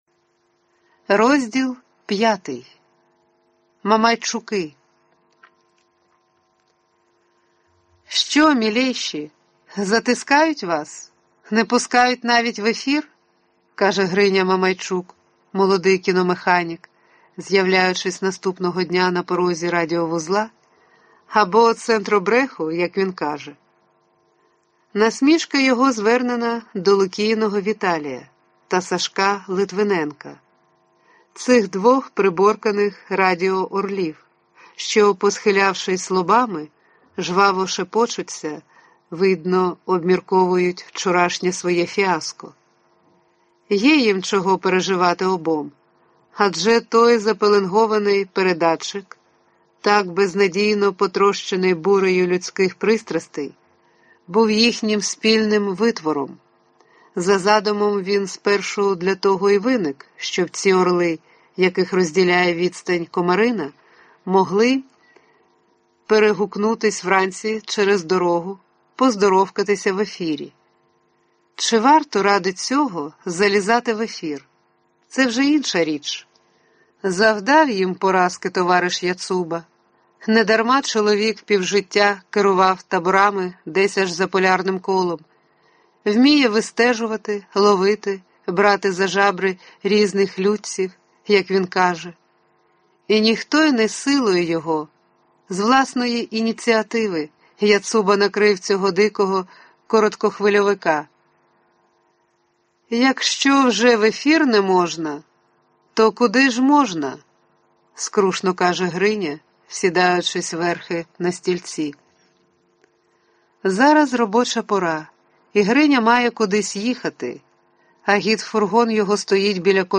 Аудиокнига Мамайчуки. Новела | Библиотека аудиокниг